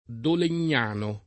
Dolegnano [ dolen’n’ # no ] top. (Friuli)